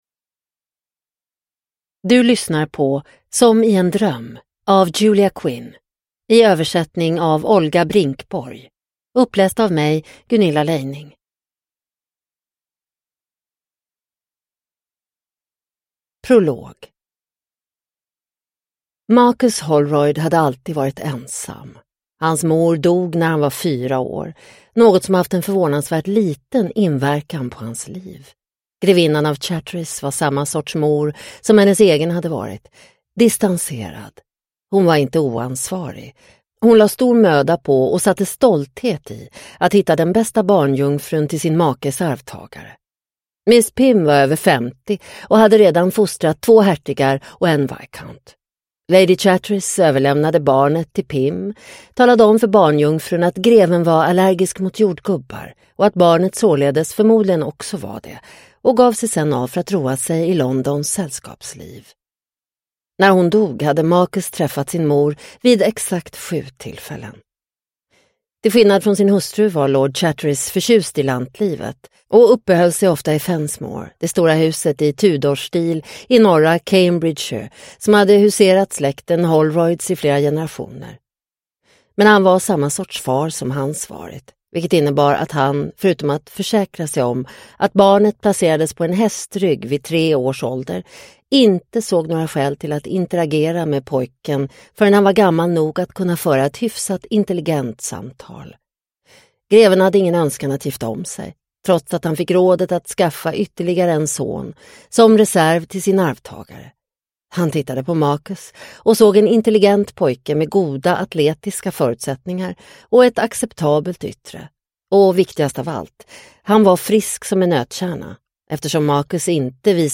Som i en dröm – Ljudbok – Laddas ner